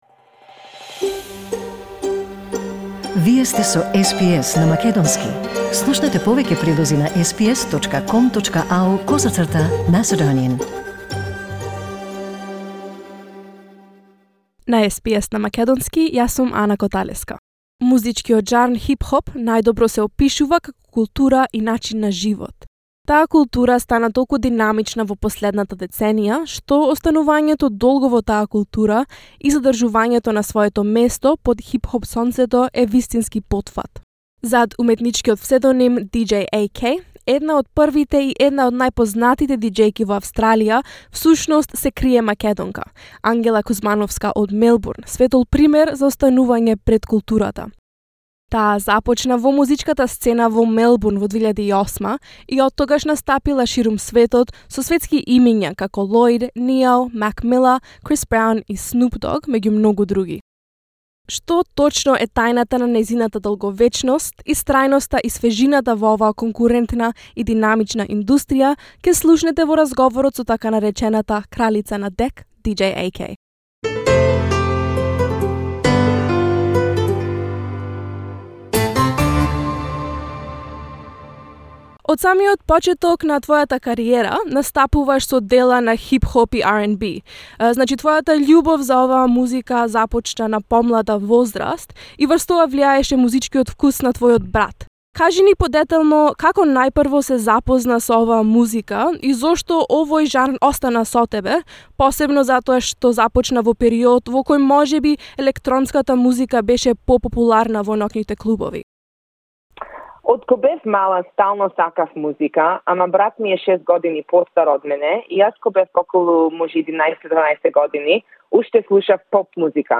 What exactly is the key to breaking through in a field primarily dominated by men, and what role does appearance play? Find out more in this interview with SBS in Macedonian.